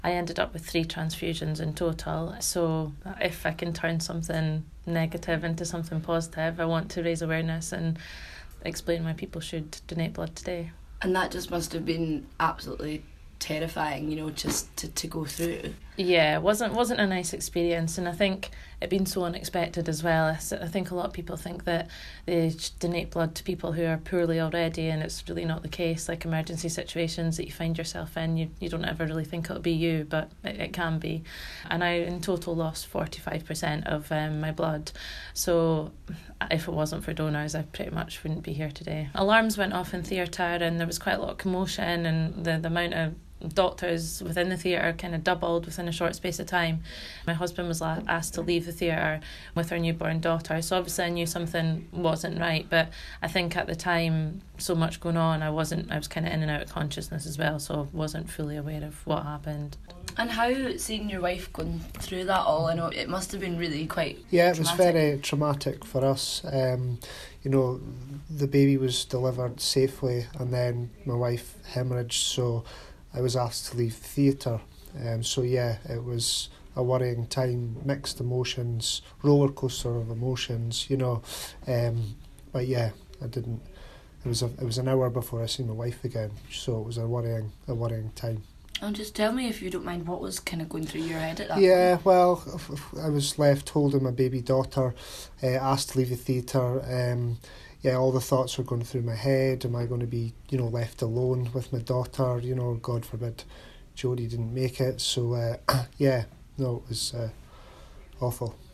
A Dundee mum is telling our reporter